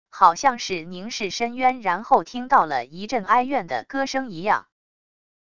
好像是凝视深渊然后听到了一阵哀怨的歌声一样wav音频